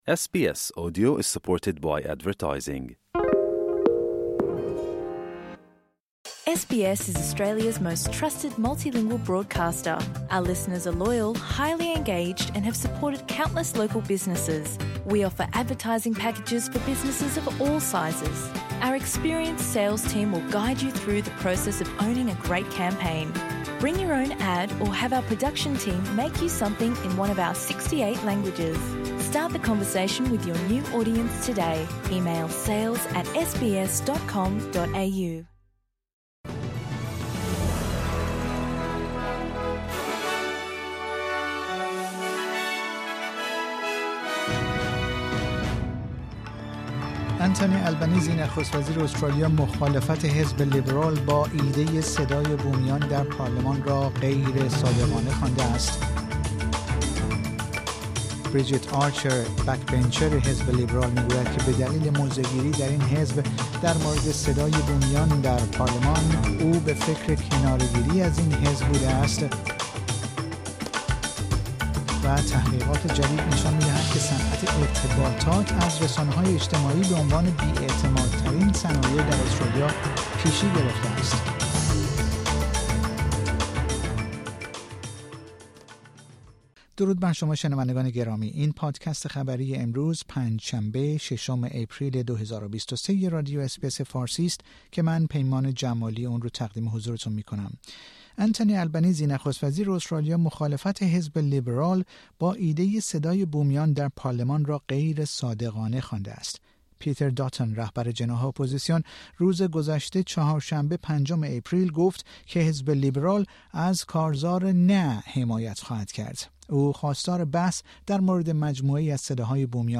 مهمترین اخبار امروز استرالیا